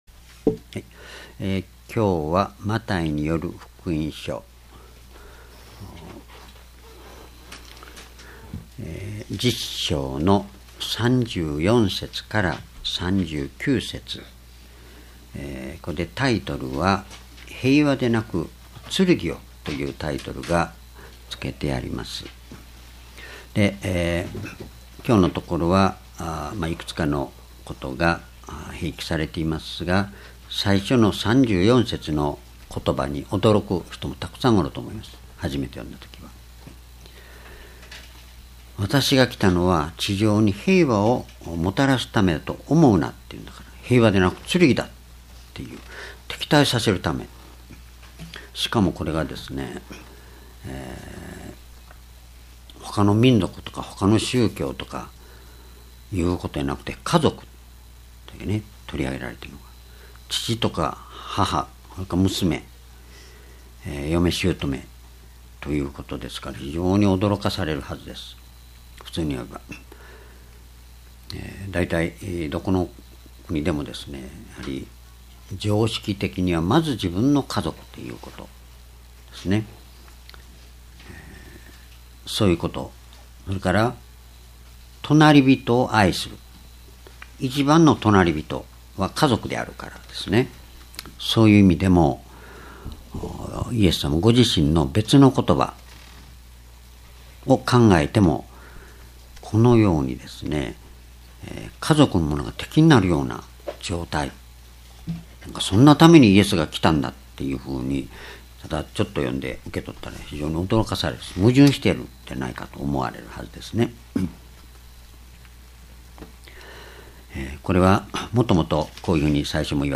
主日礼拝日時： 2017年6月25日 主日 聖書講話箇所 「二種類の平和」 マタイ福音書10章34節-39節 ※視聴できない場合は をクリックしてください。